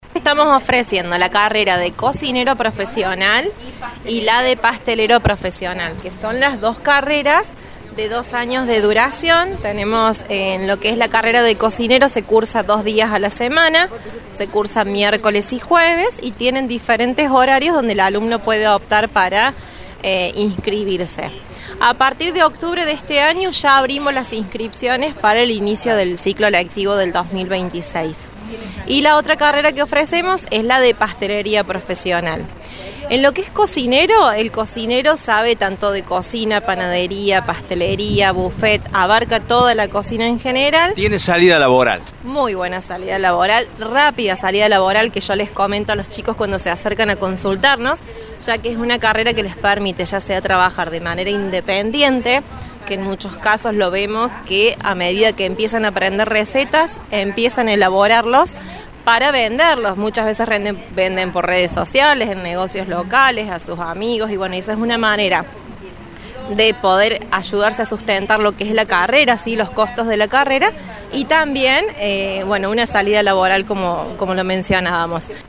Radio Show dialogó con algunas instituciones que participan del recorrido.